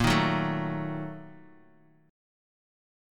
A#M7sus4#5 chord